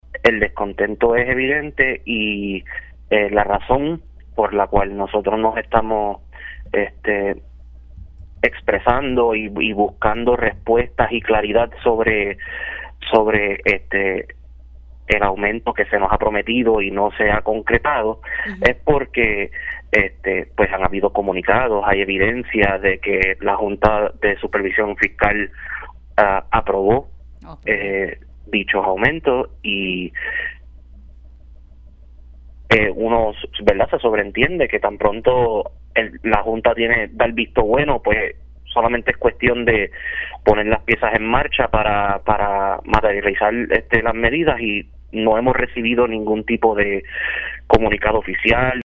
509-PARAMEDICO-DESCONTENTO-ANTE-FALTA-DE-AUMENTO-SALARIAL-ORANGE-FLU-ESTE-FIN-DE-SEMANA.mp3